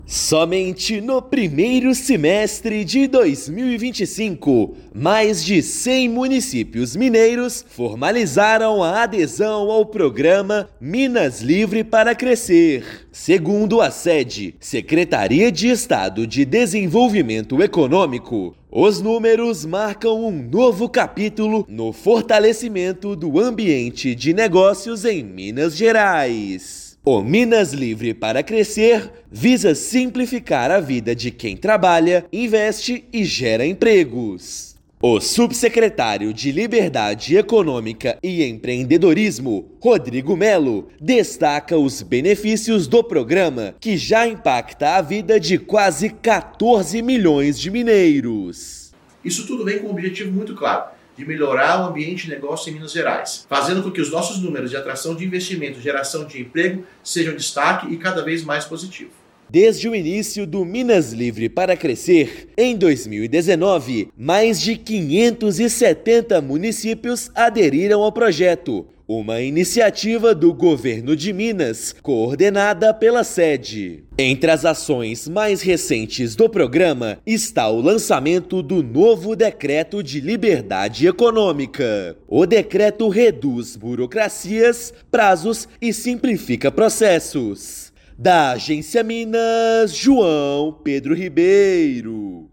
[RÁDIO] Estado avança na desburocratização com mais de cem municípios aderindo ao Minas Livre para Crescer só em 2025
Programa promove melhorias no ambiente de negócios, atração de investimentos e geração de emprego e renda em 570 cidades. Ouça matéria de rádio.